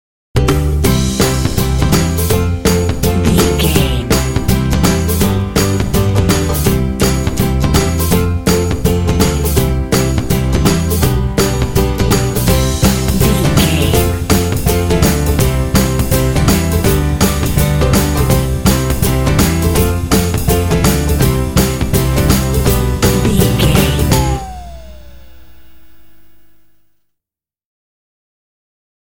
Uplifting
Ionian/Major
energetic
bouncy
acoustic guitar
bass guitar
drums
piano
indie
pop
contemporary underscore